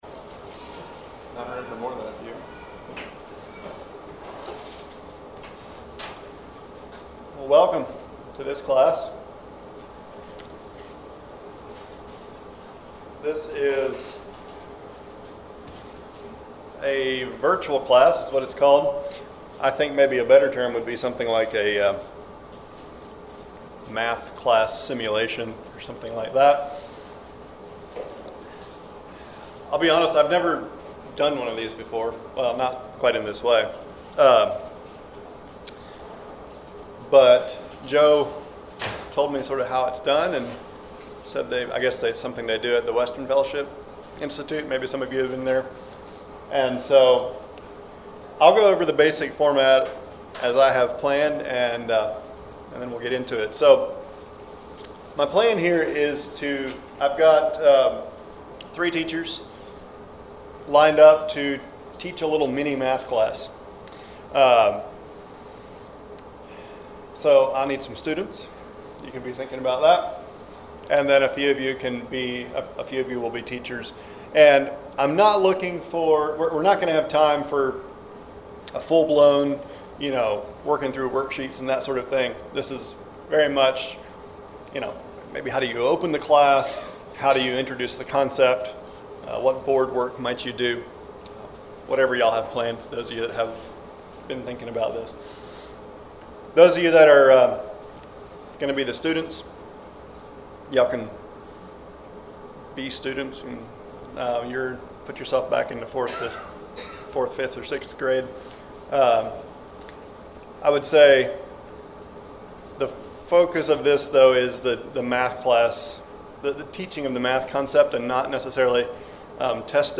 01:04 Download Learn from colleagues in this interactive workshop. We’ll watch several teachers model a math class, discuss their strengths and suggest improvements, and try to answer any questions you may have.